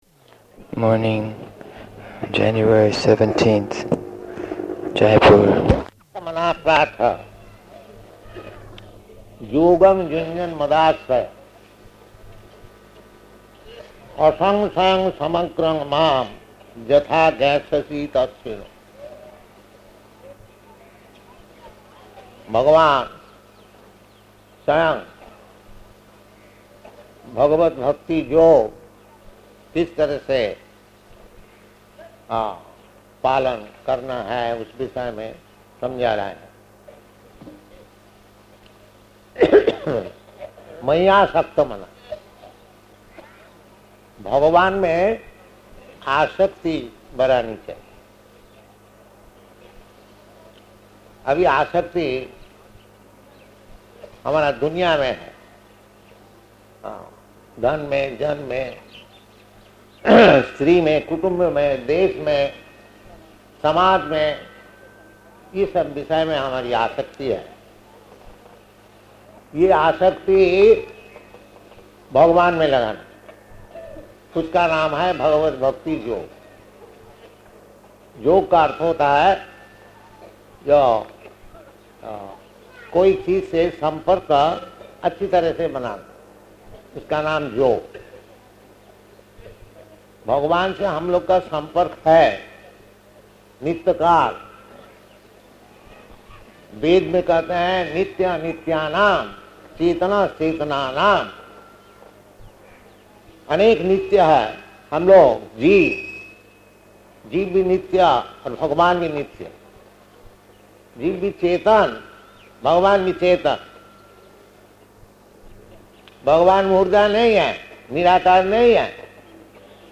Lecture in Hindi
-- Type: Lectures and Addresses Dated: January 17th 1972 Location: Jaipur Audio file